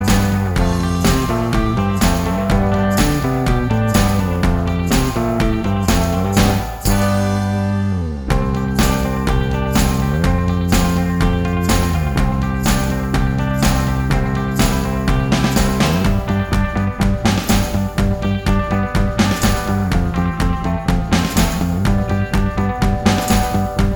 Minus Rhythm Guitar Pop (1980s) 2:46 Buy £1.50